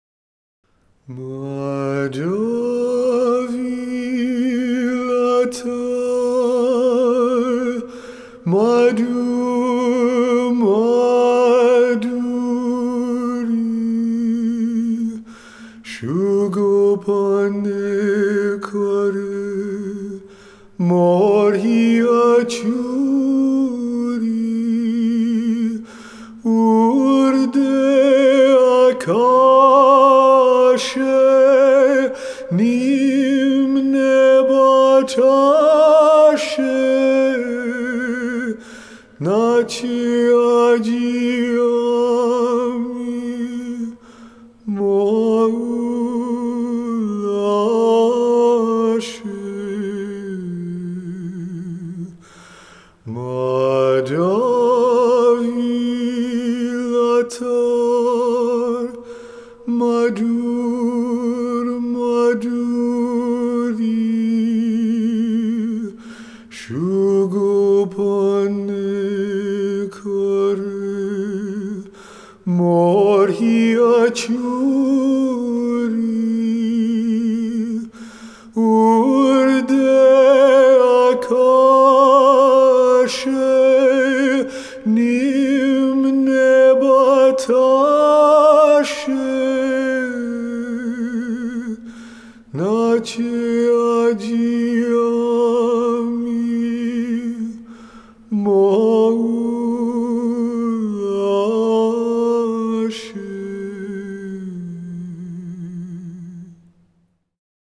Das ist sein erstes Album, auf dem er solo singt.
Flute Introduction